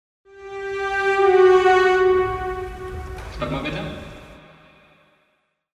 Наверняка все слышали в берличниках этот забавный релиз у челл на F3 и F#3, но вот что он там говорит?